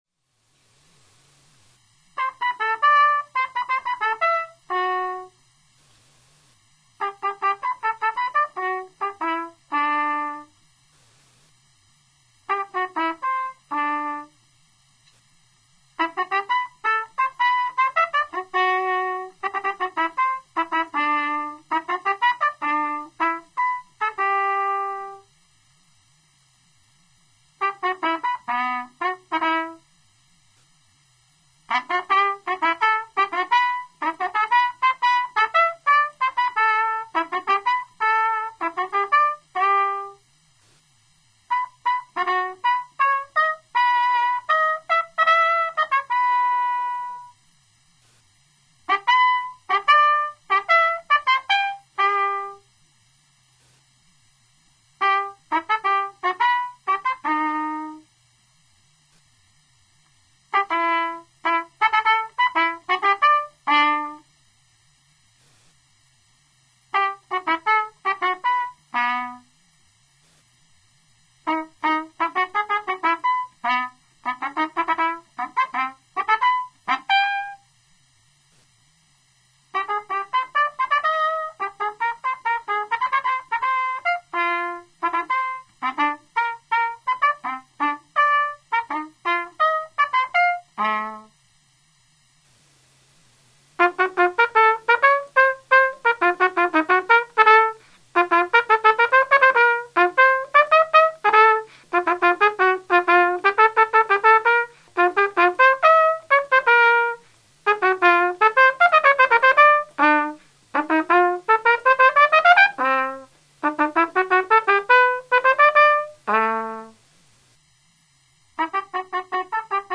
Solo Trumpet
Willwerth Essays for Unaccompanied Trumpet